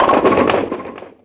bowling07.mp3